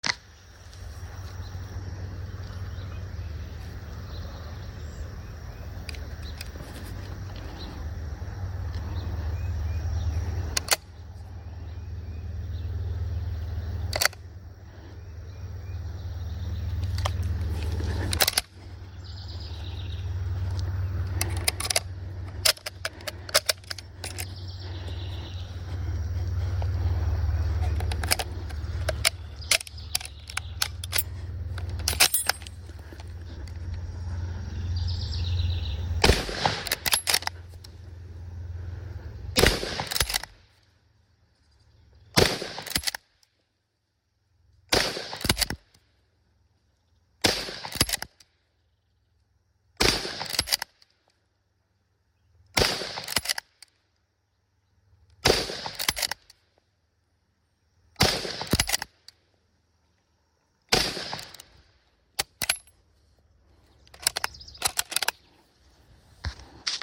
lee_enfield_firing_sounds.mp3